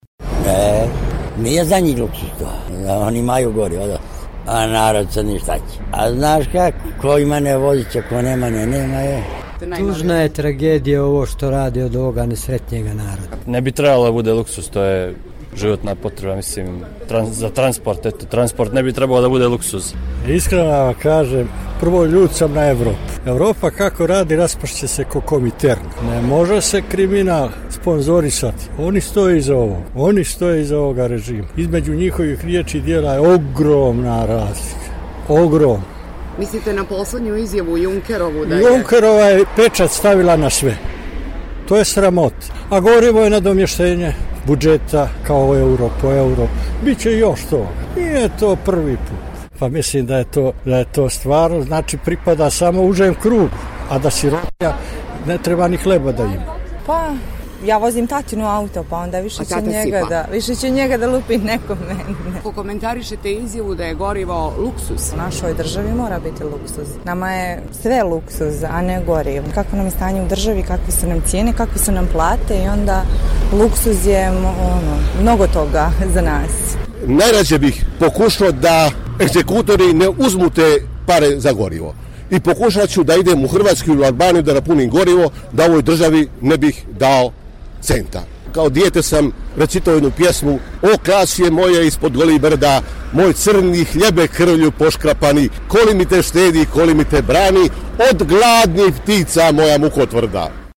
Gradjani Podgorice